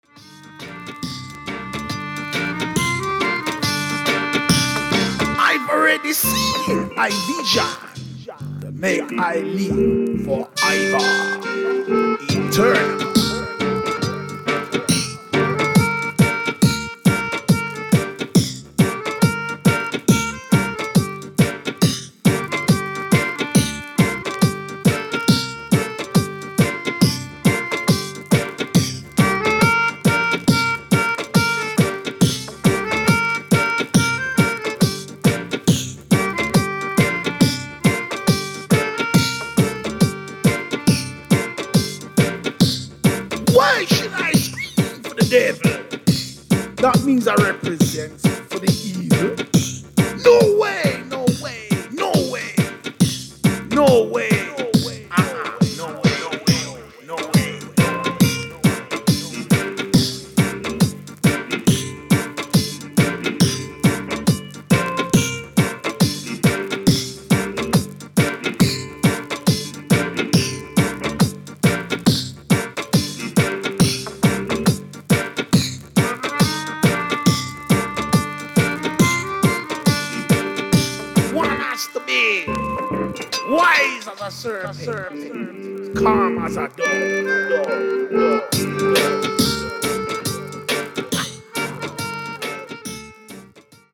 UK New Roots